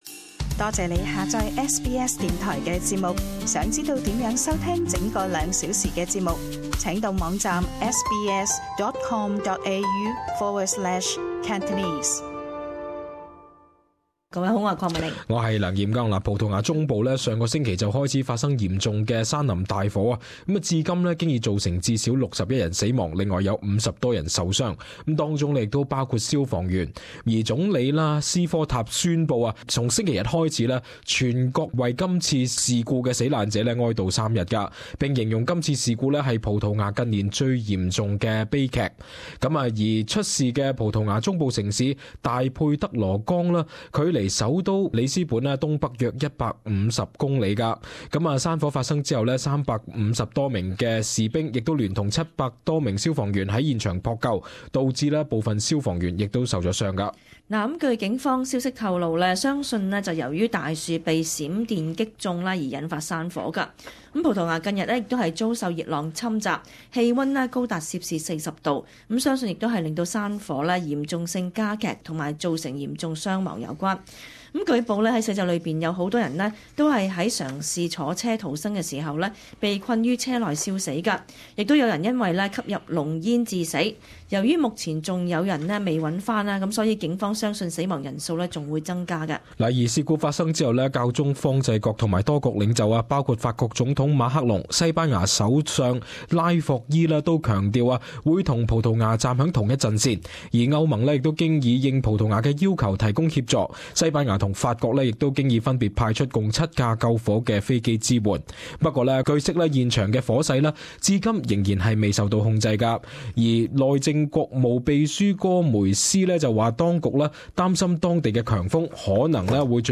【時事報導】葡萄牙山火至少 61 人死